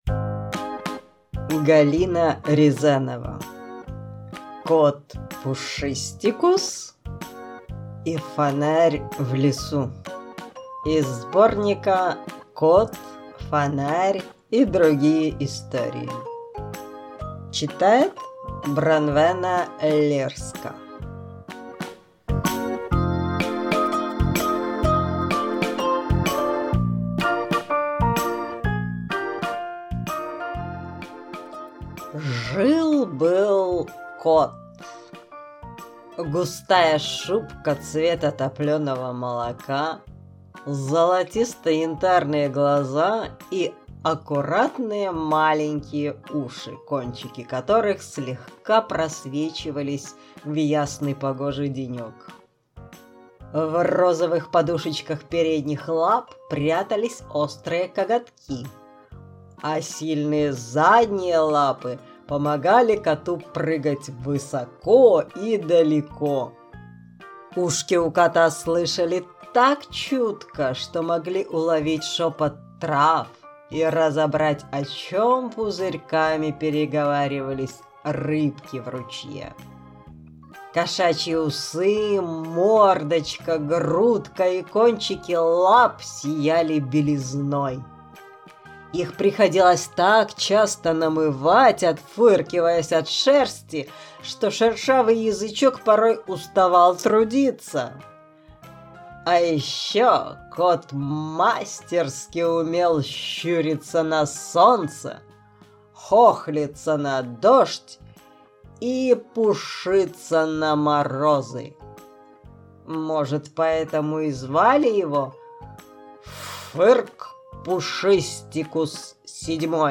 Аудиокнига: